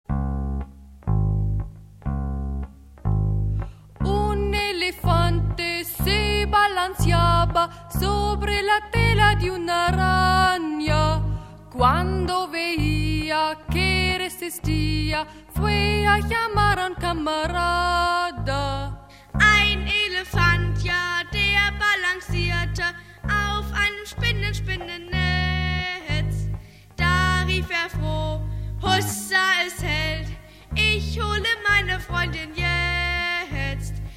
Kinder- / Jugendbuch Gedichte / Lieder